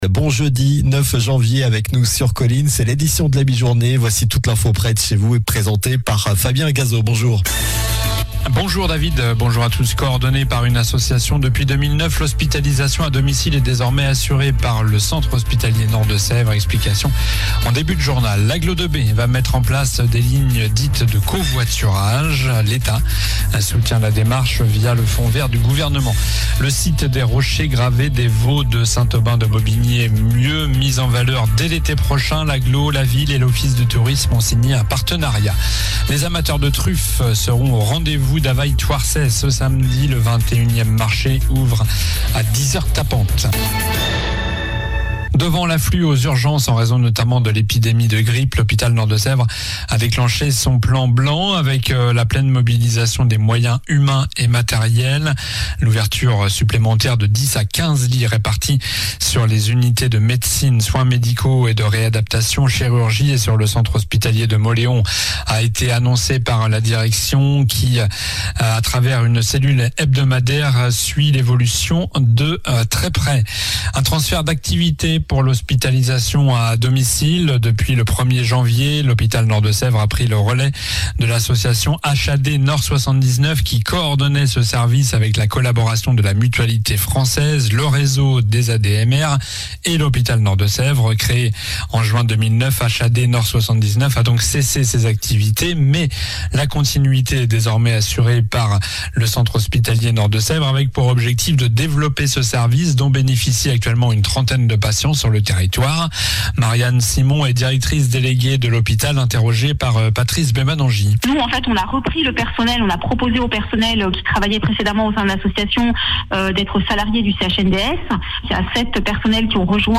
Journal du jeudi 09 janvier (midi)